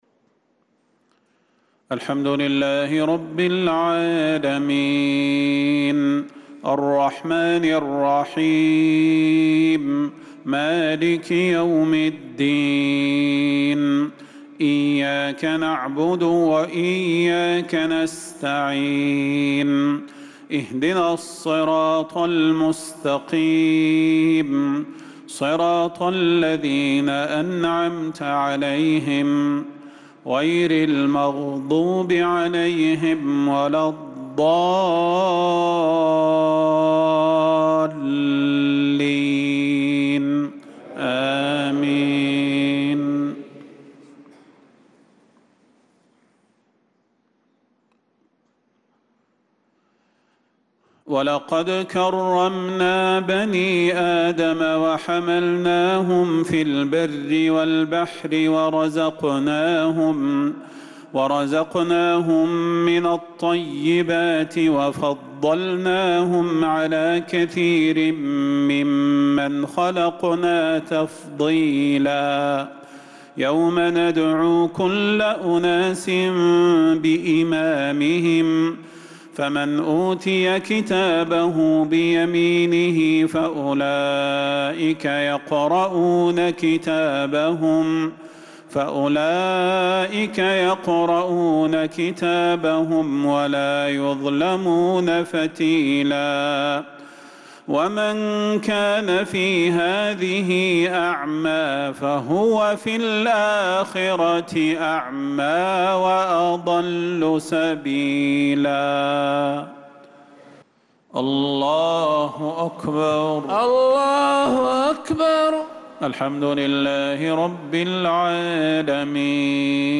صلاة المغرب للقارئ صلاح البدير 4 ذو القعدة 1445 هـ
تِلَاوَات الْحَرَمَيْن .